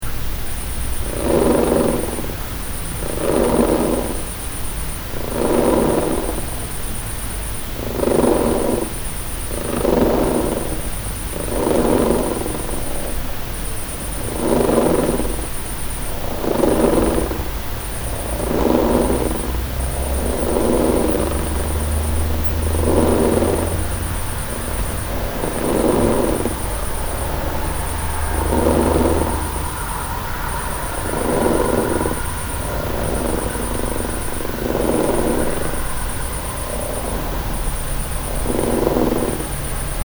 Meist werden die Geräusche als Katzenschnurren oder Schnarchen und gruselig, bezeichnet.
Schnarchen, schnurren eines Waschbär, Marder, Katze ...?
Nein, tatsächlich stammen diese seltsamen, "gruseligen" Geräusche aus einem Wespennest
Gemeine Wespe
Zimmerdecke, 01:00 Uhr nachts.
Man geht davon aus, dass diese "Schnarchgeräusche" vom Wespenvolk, meist in der Nacht, durch Flügelschlagen in einer Art "La-Ola-Welle" erzeugt werden.
Wespen.mp3